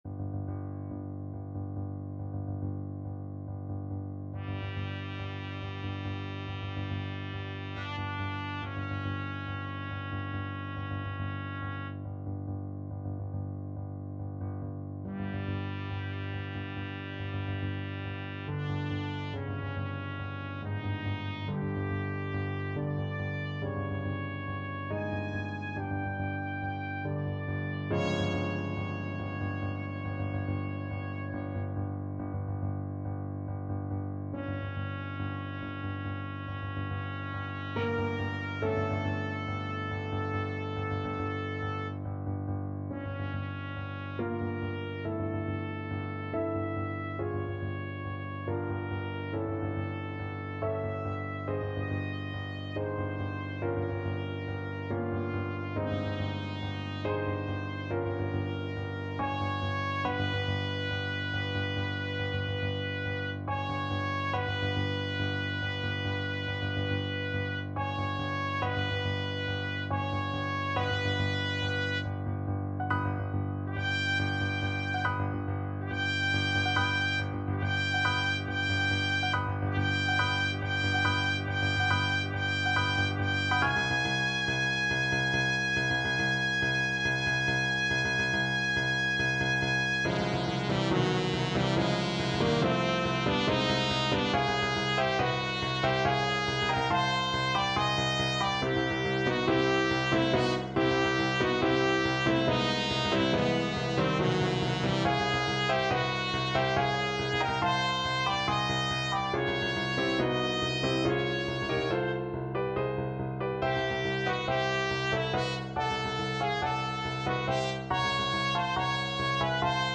Classical Holst, Gustav Mars from The Planets Trumpet version
Allegro = 140 (View more music marked Allegro)
5/4 (View more 5/4 Music)
A minor (Sounding Pitch) B minor (Trumpet in Bb) (View more A minor Music for Trumpet )
Trumpet  (View more Advanced Trumpet Music)
Classical (View more Classical Trumpet Music)